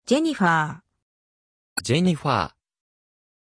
Aussprache von Jennifer
pronunciation-jennifer-ja.mp3